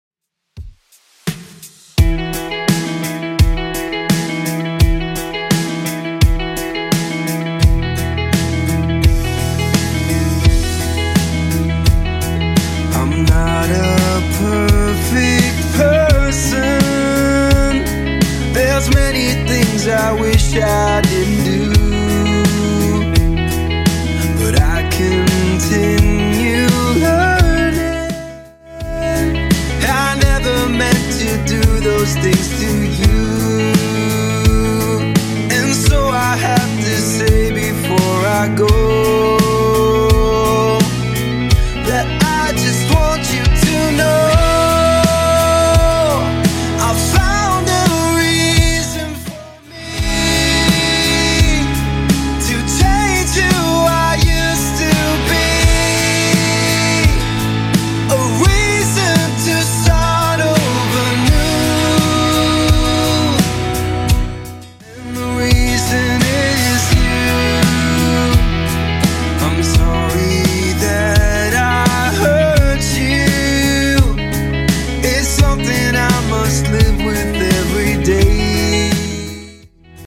Genre: 90's
BPM: 150